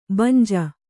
♪ banja